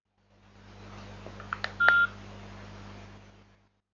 Dieser Sound erklingt, wenn bei einem Nokia Handy die automatische Tastensperre aktiviert ist.